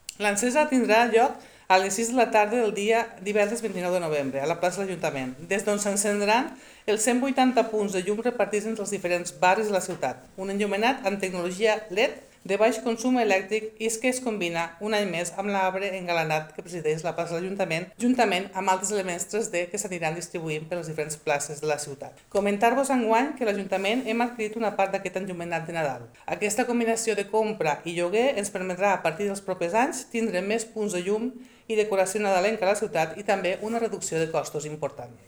La regidora de Comerç, Sònia Rupérez, ha destacat que l’enllumenat de la ciutat comptarà amb un total de 180 punts de llum, repartits per diversos barris, utilitzant tecnologia LED de baix consum elèctric i com novetat de l’enllumenat ha estat adquirit per optimitzar els costos a llarg termini.